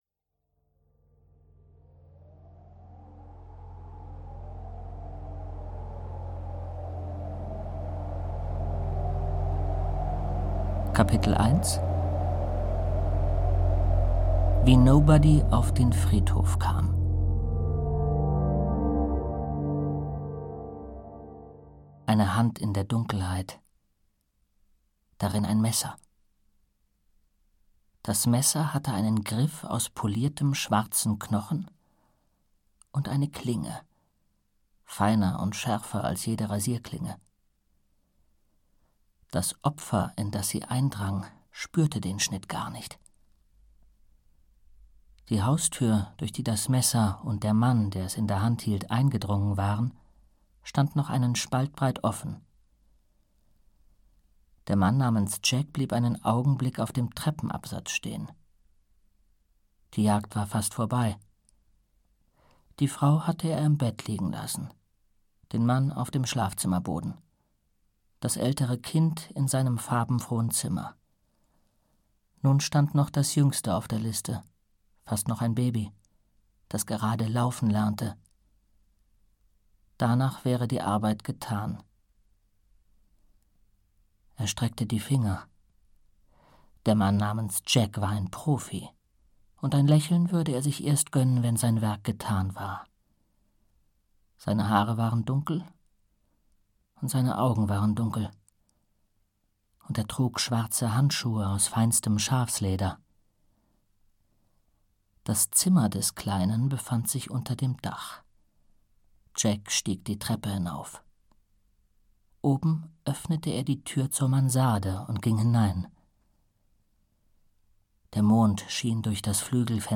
Audio kniha
Ukázka z knihy
• InterpretJens Wawrczeck